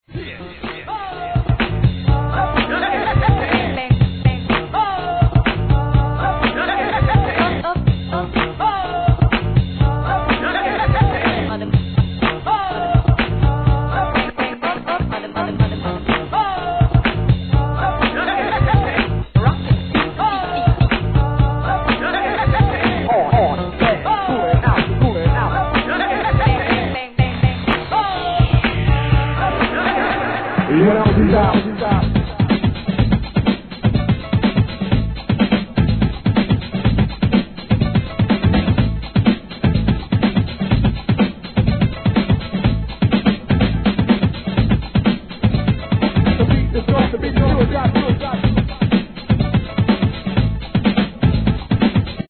HIP HOP/R&B
OLD SCHOOLなブレイク物で、ダンサーにも重宝しそうです!!